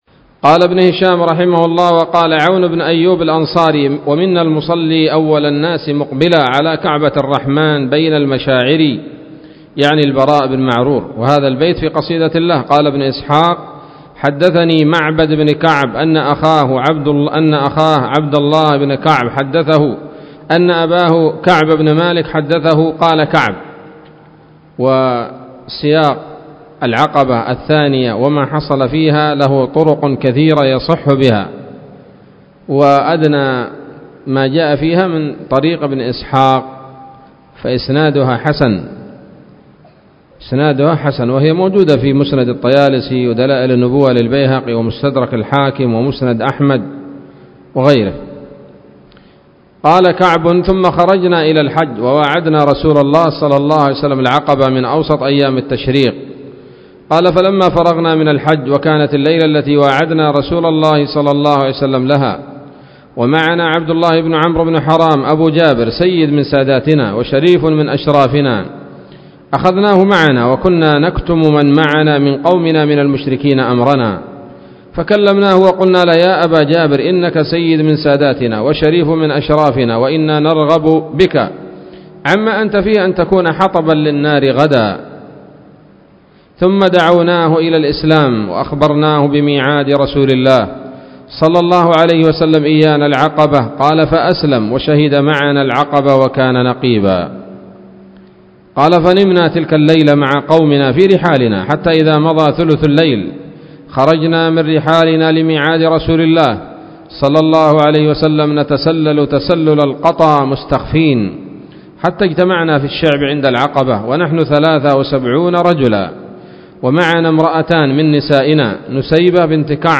الدرس الرابع والستون من التعليق على كتاب السيرة النبوية لابن هشام